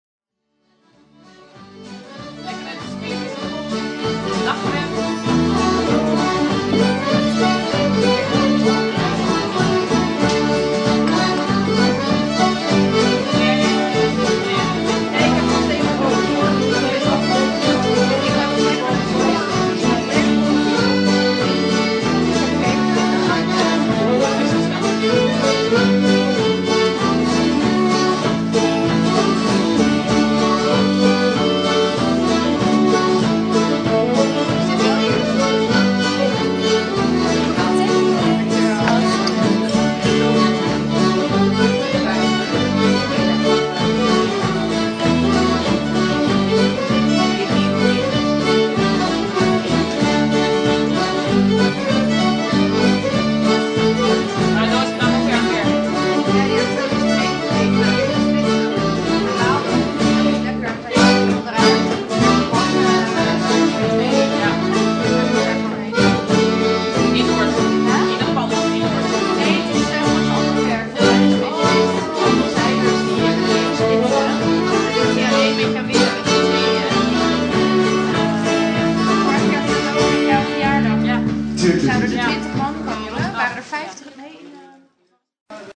In Overleek stoppen we in Herbergh 't IJsselmeer voor een warm drankje. De band Daddy-O is juist bezig met het opbouwen van hun set en even later worden we getrakteerd op Ierse en Schotse folkmuziek.